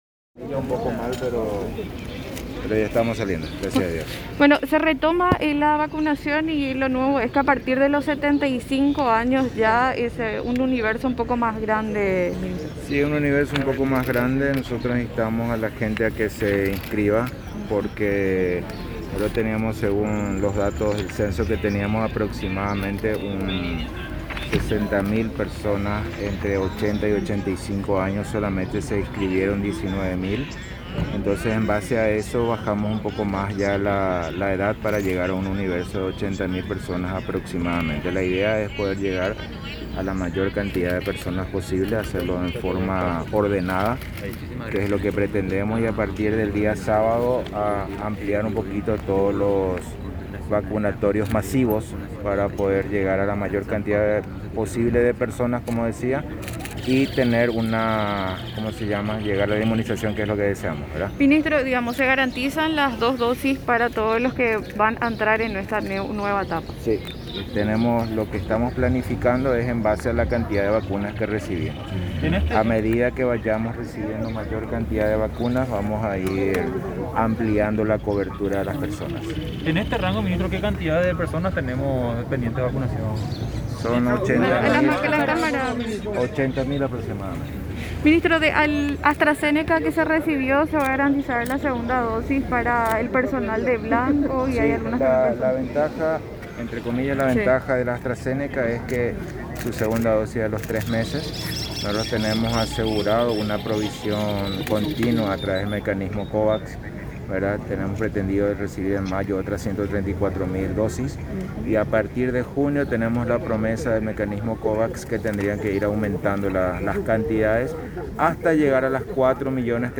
17-JULIO-BORBA-HOSPITAL-SAN-PABLO.mp3